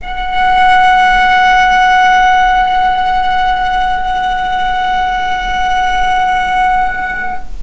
healing-soundscapes/Sound Banks/HSS_OP_Pack/Strings/cello/ord/vc-F#5-pp.AIF at b3491bb4d8ce6d21e289ff40adc3c6f654cc89a0
vc-F#5-pp.AIF